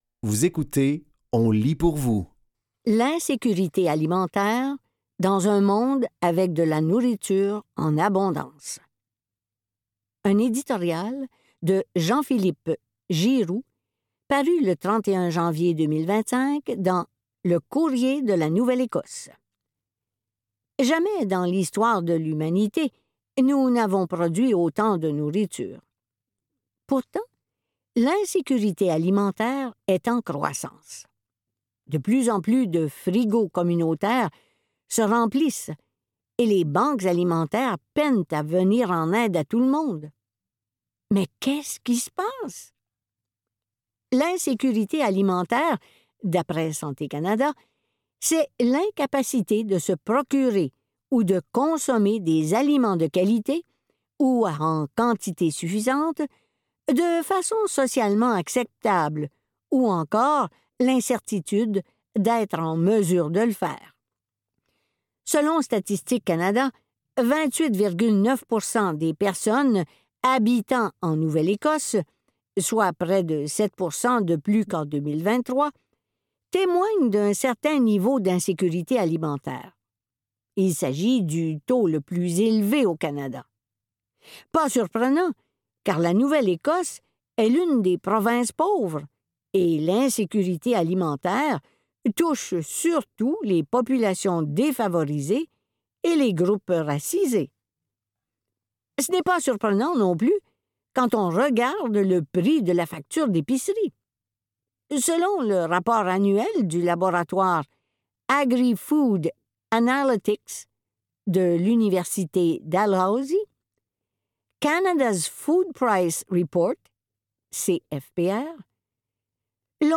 Dans cet épisode de On lit pour vous, nous vous offrons une sélection de textes tirés du média suivant : Le Courrier de la Nouvelle-Écosse, Le Devoir, Le Libraires et La Presse.